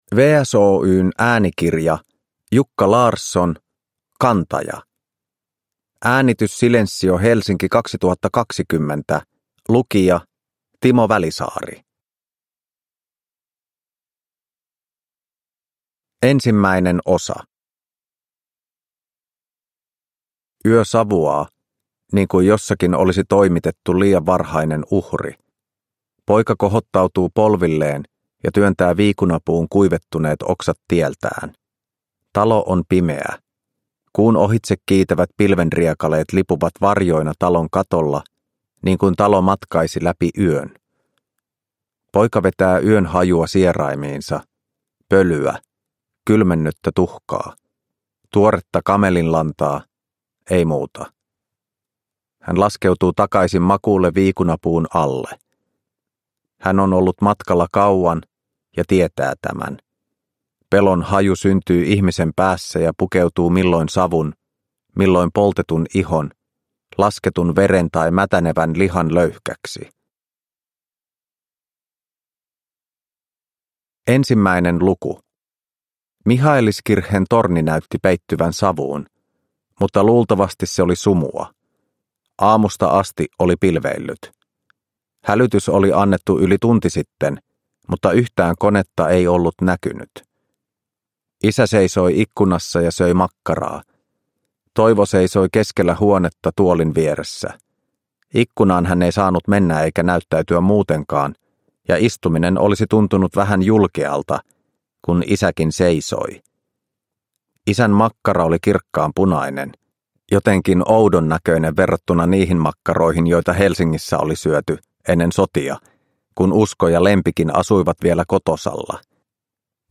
Kantaja – Ljudbok – Laddas ner